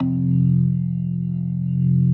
B3LESLIE F 2.wav